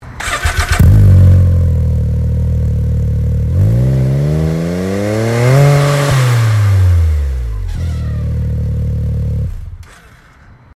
• Low-tone, consistent sound quality
X3_Turbo_Back_exhaust.mp3